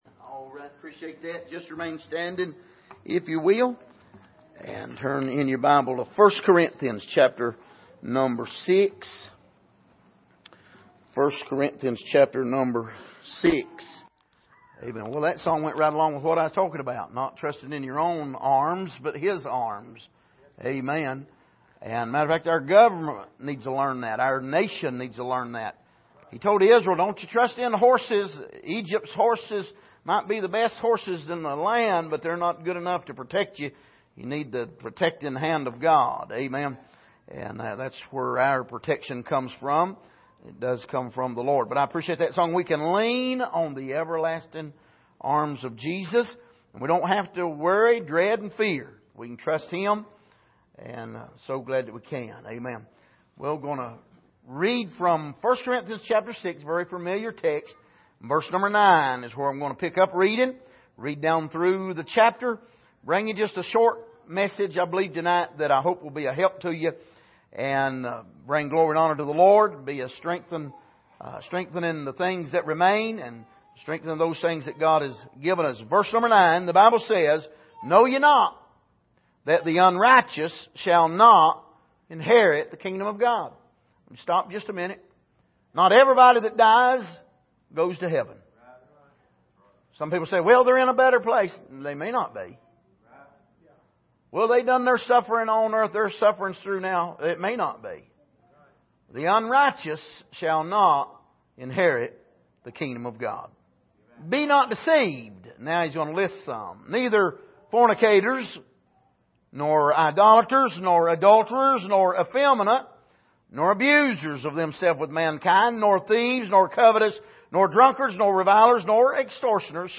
Passage: 1 Corinthians 6:9-20 Service: Sunday Evening